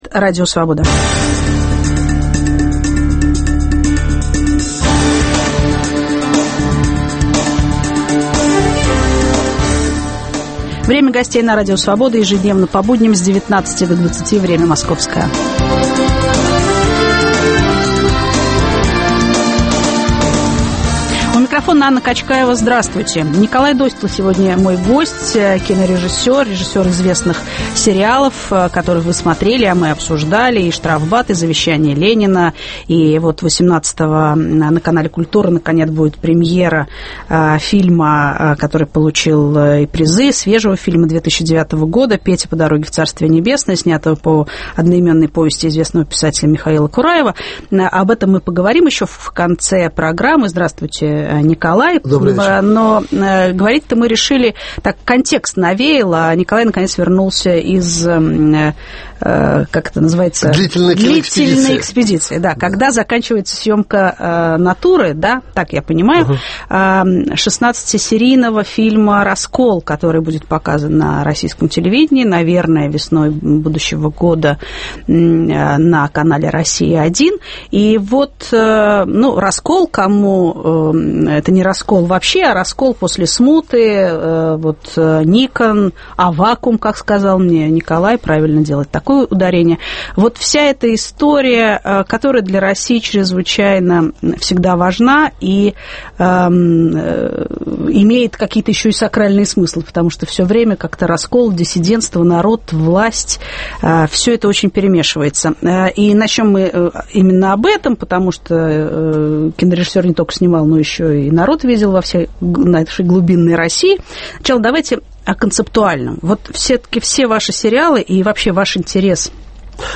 Николай Досталь заканчивает многосерийный телероман "Раскол". О народе, истории, власти и раскольниках с кинорежиссером беседует Анна Качкаева.